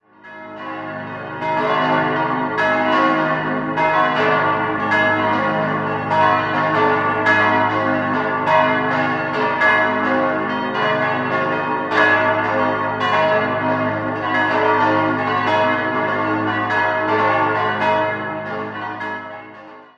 Der heutige Bau stammt überwiegend noch aus dem 17. Jahrhundert und wurde von Graubündener Baumeistern errichtet. Aus dem Jahr 1885 stammt der Westteil mit der Turmfassade. 6-stimmiges Geläut: a°-cis'-e'-fis'-gis'-h' D ie Glocken 1 bis 5 wurden 1947/48 von Karl Czudnochowsky in Erding gegossen, Glocke 6 (Sterbeglocke) im Jahr 1852 von Oberascher.